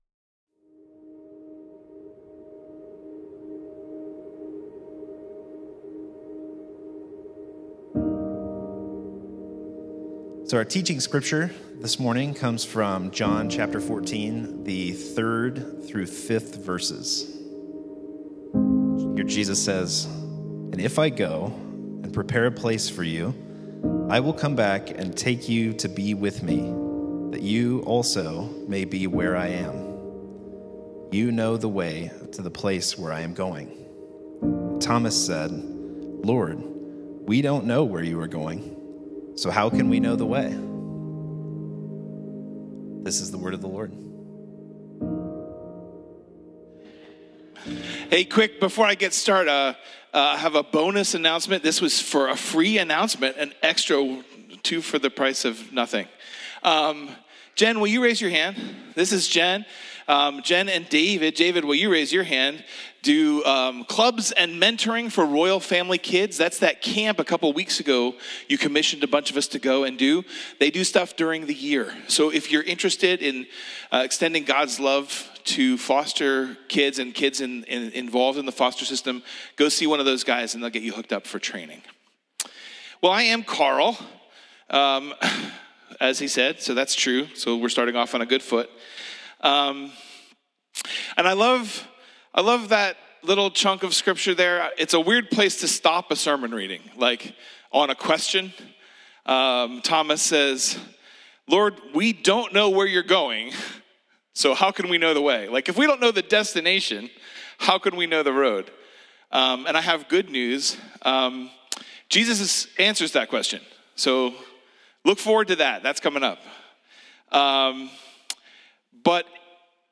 Sermons | Central Vineyard Church